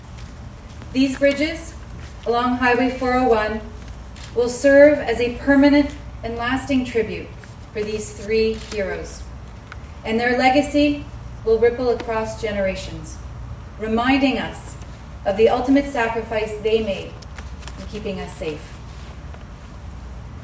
Minster of Transportation Caroline Mulroney touched on the importance of the dedications.
Minster of Transportation Caroline Mulroney was on hand to speak about the bridge dedications.
mulroney-bridge-dedication.wav